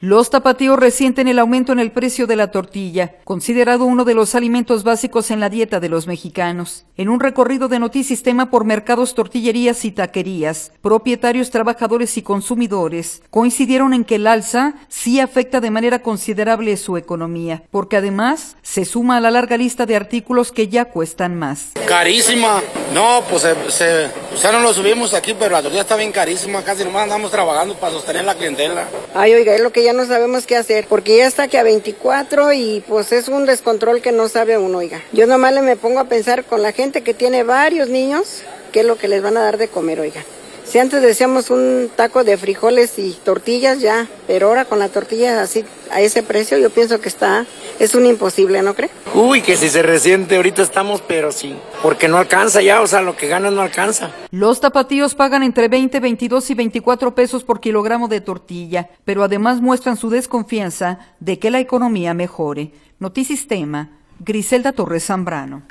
En un recorrido de Notisistema por mercados, tortillerías y taquerías, propietarios, trabajadores y consumidores, coincidieron en que el alza, sí afecta de manera considerable su economía porque, además, se suma a la larga lista de artículos que ya cuestan más “¡Carísima!